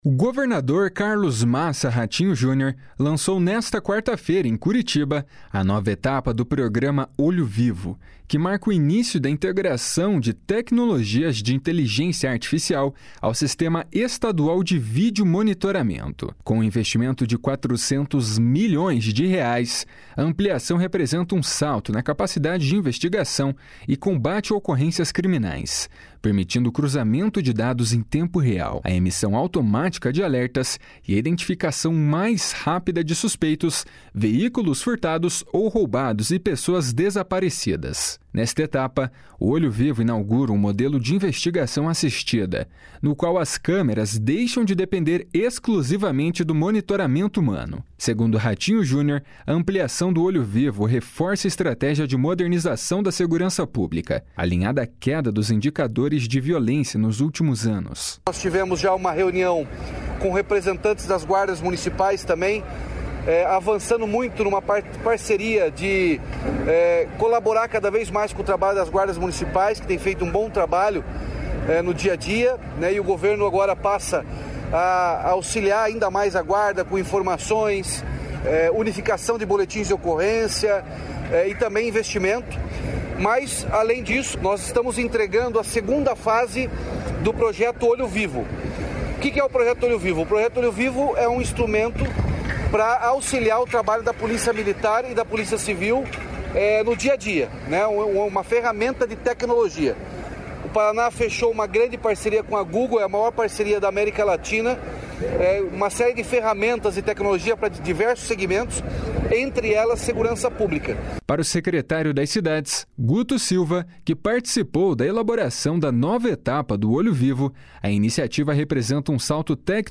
// SONORA RATINHO JUNIOR //
// SONORA HUDSON LEONCIO //